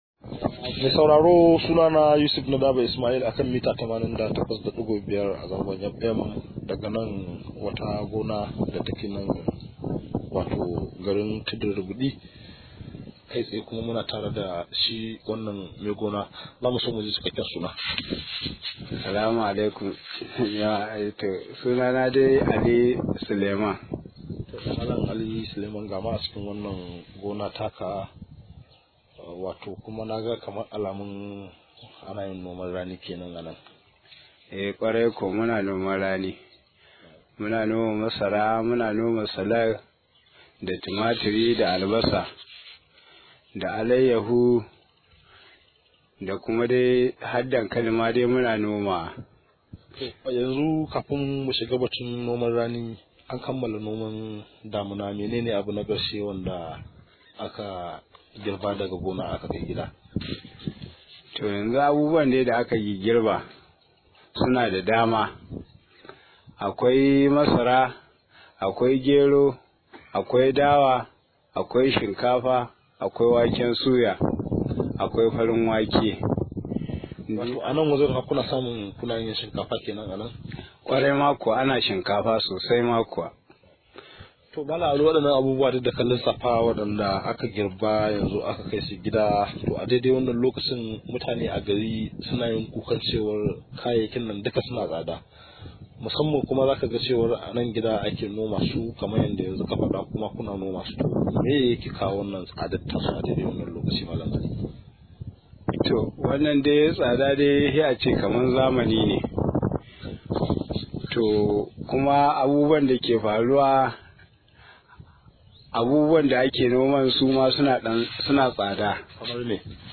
Rahoto: Tsadar kayan aikin noma ke sanya Hatsi tsada – Manomi